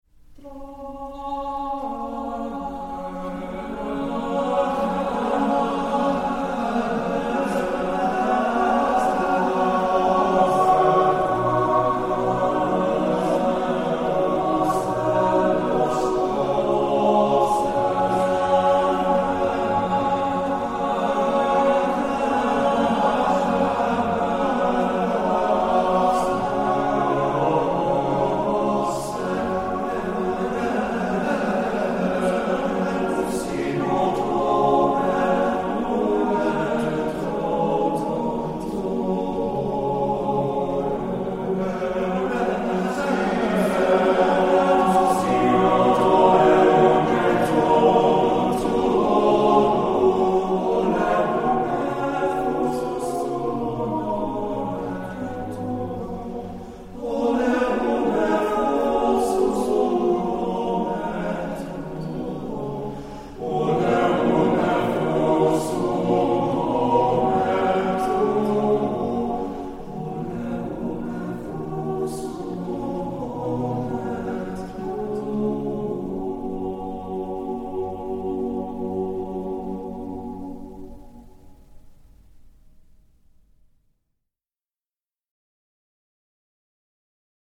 Voicing: TTBB a cappella